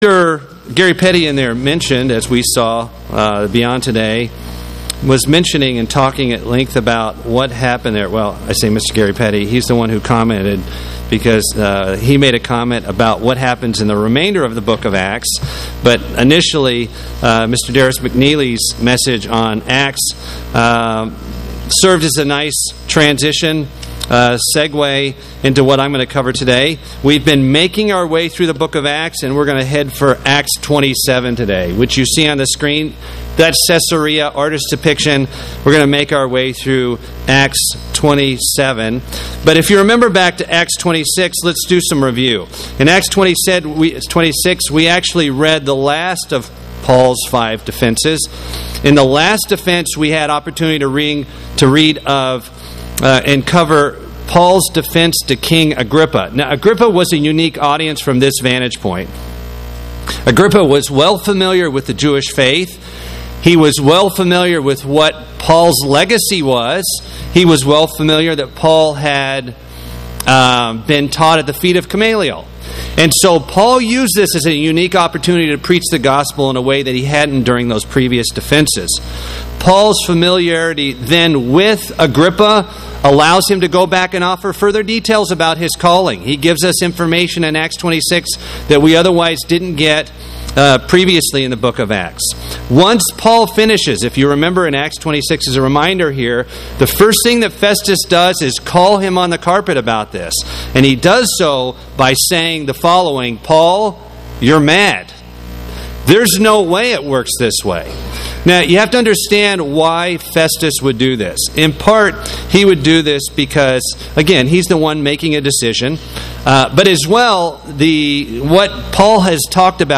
Rome Paul Luke Atonement Ship wreck sermon book of Acts Studying the bible?